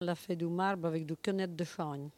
Langue Maraîchin
Patois
Catégorie Locution